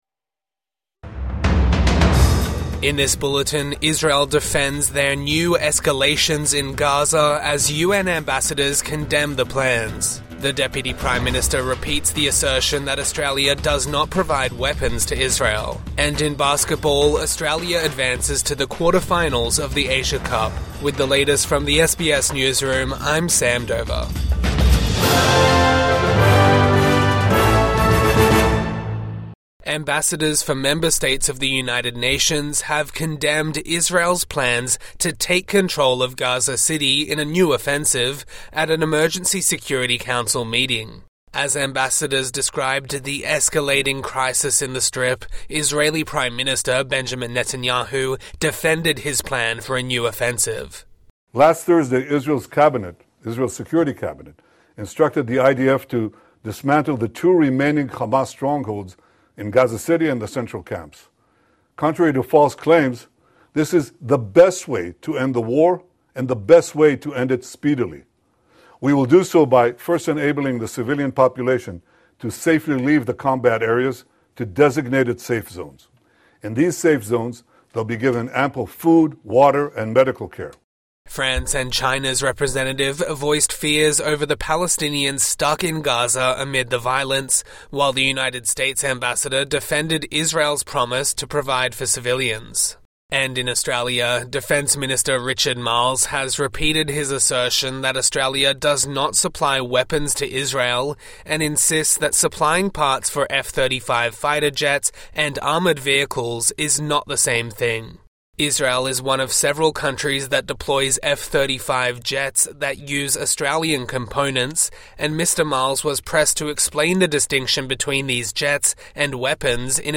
Federal government defends supply of jet parts to Israel | Morning News Bulletin 11 August 2025